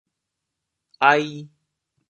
国际音标 [ai]